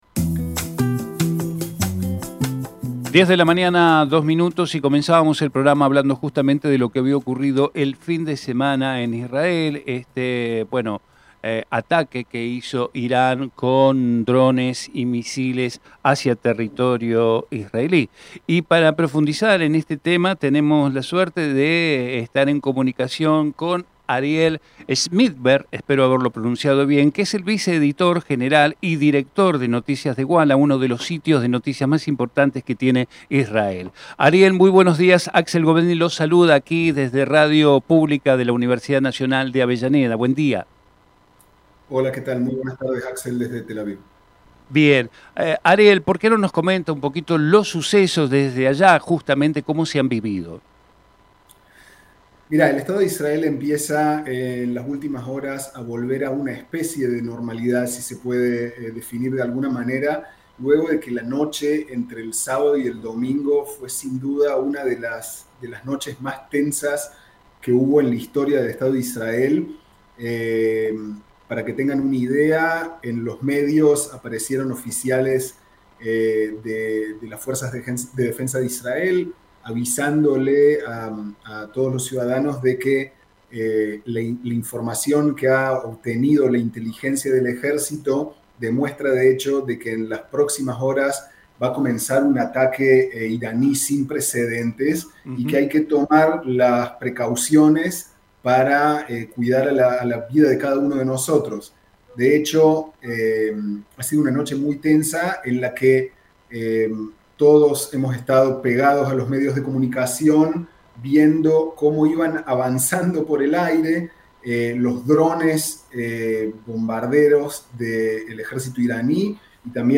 Compartimos con ustedes la entrevista